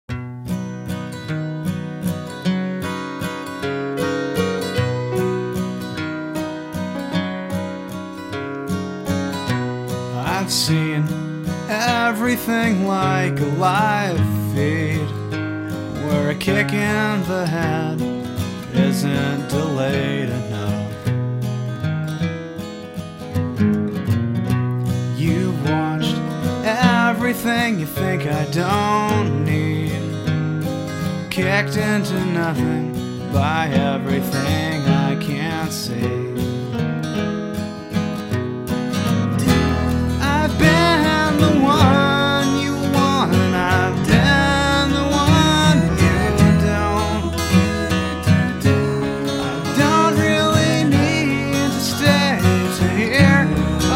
Your Minneapolis-based musician and songwriter